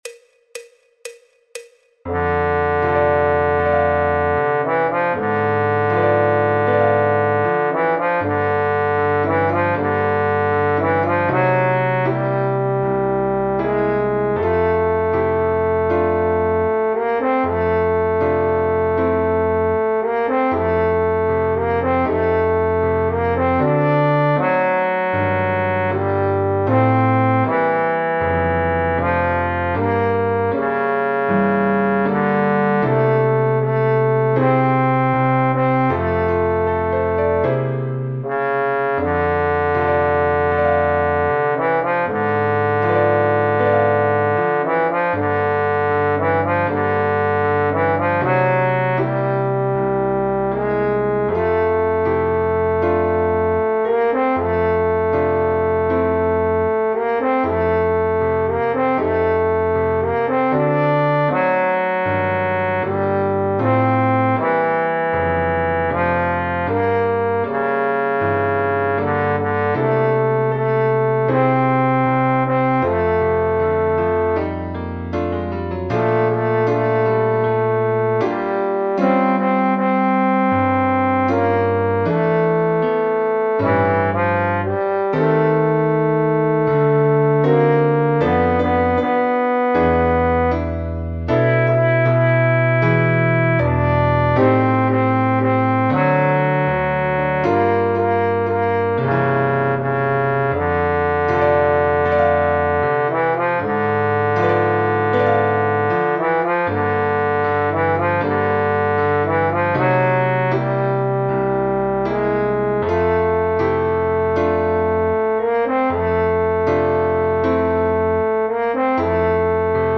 El MIDI tiene la base instrumental de acompañamiento.
Trombón / Bombardino
Fa Mayor
Jazz, Popular/Tradicional